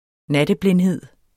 Udtale [ ˈnadəˌblenˌheðˀ ]